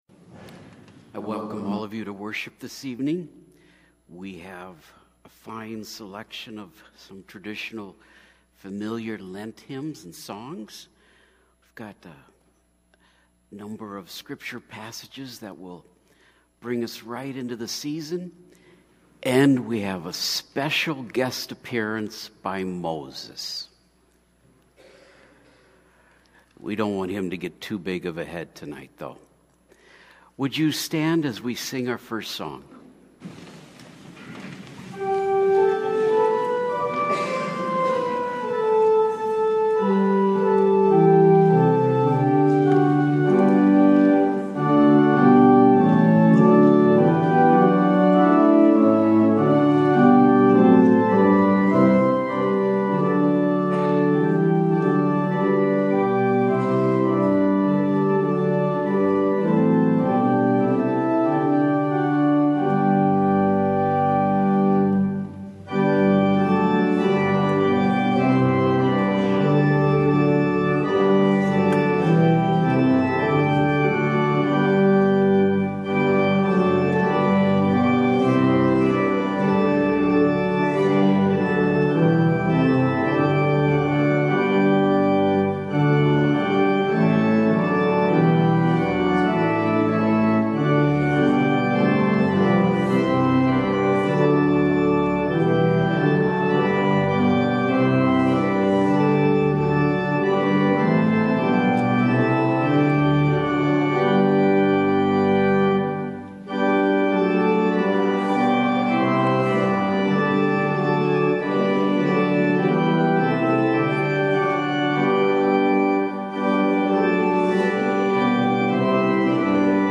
Feb 21 / Wed Eve – Lenten Worship Service audio